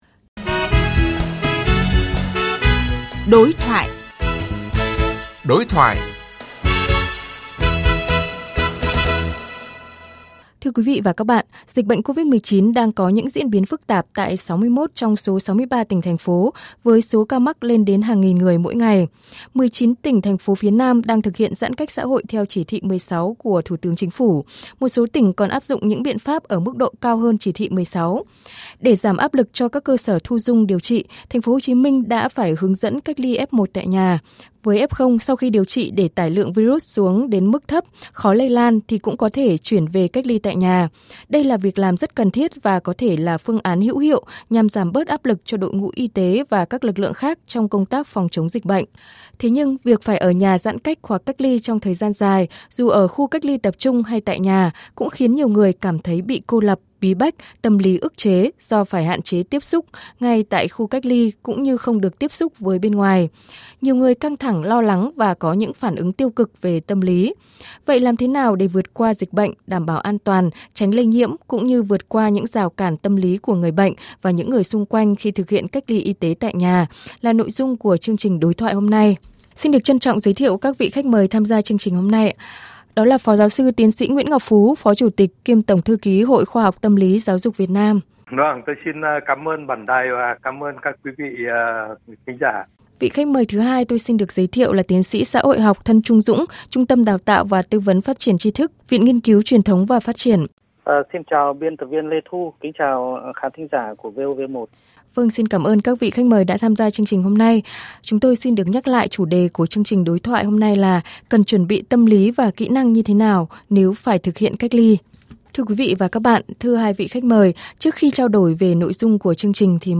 Kính mời các quý vị thính giả cùng nghe theo băng phát tin của VOV1.